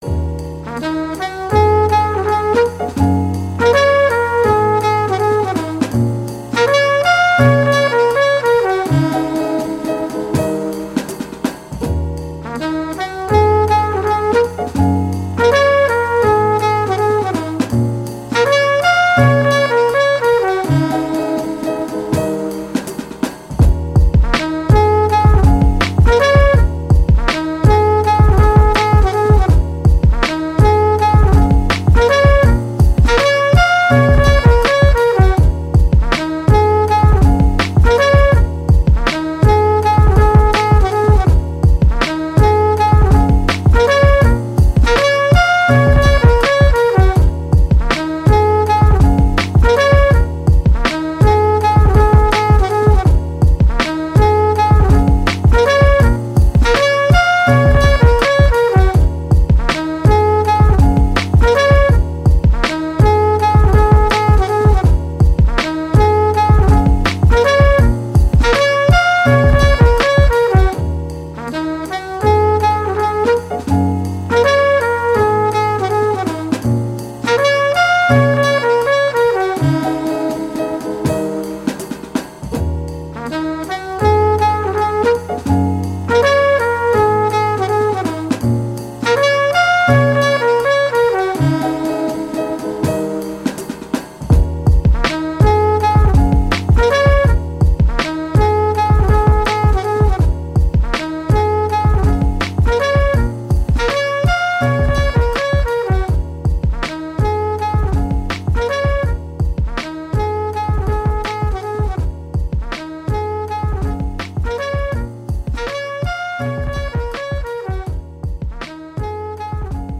Atlanta undgerground sound
instrumental album